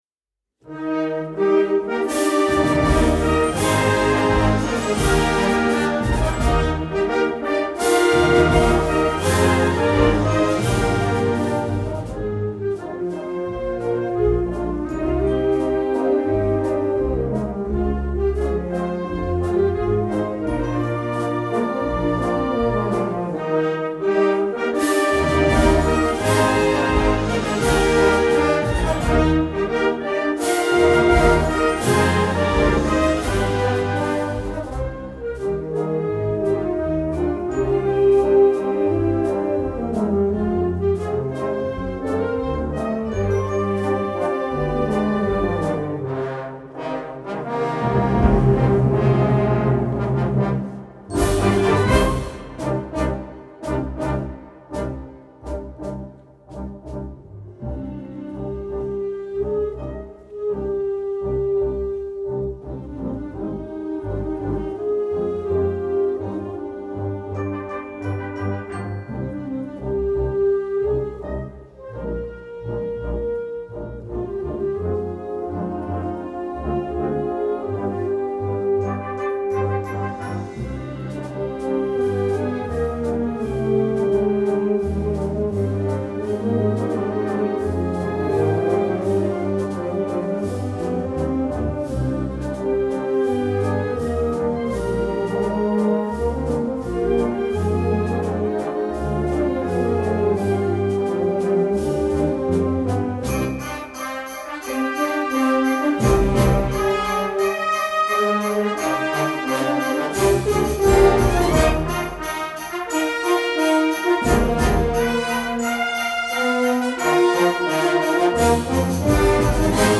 Genre: Dianero